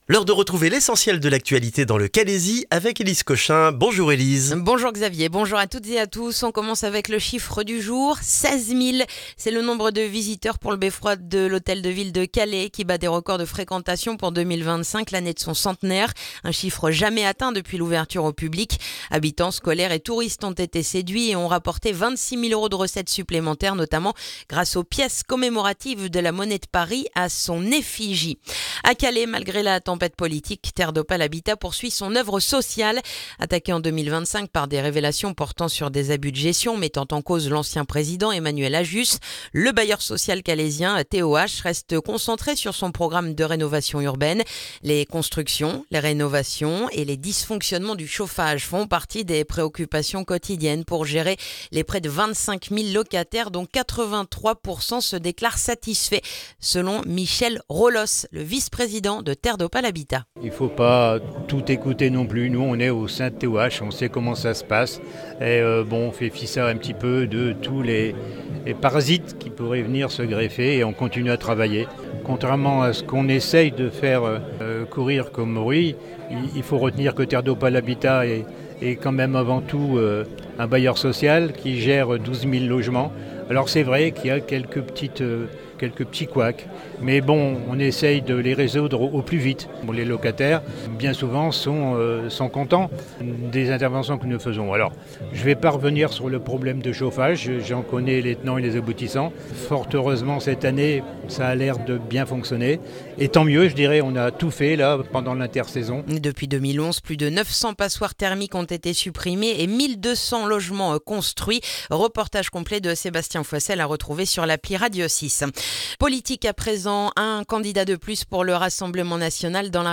Le journal du lundi 19 janvier dans le calaisis